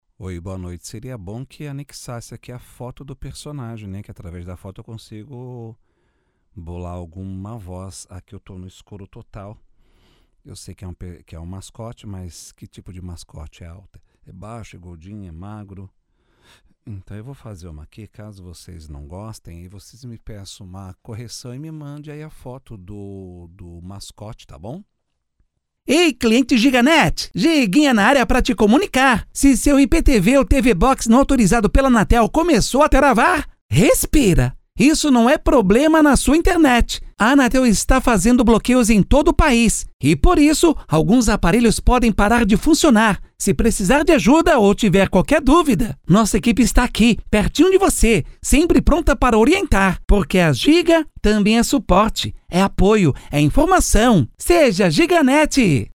Oi, envie a referencia pra gravação de hoje, pode gravar num tom bem animado, UP, mais "acelerado" pq o personagem está muito animado e feliz!